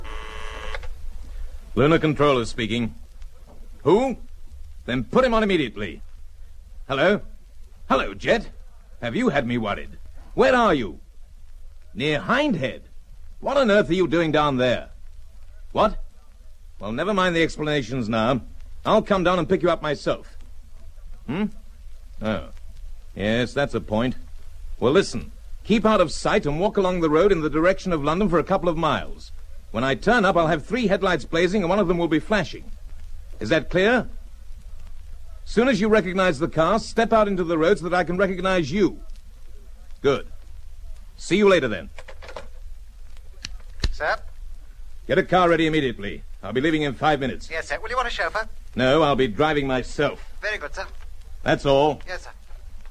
In dit geval is het een kwestie van uitspraak, waarbij ik aanneem dat de Britse versie de juiste is van “Hindhead” uit.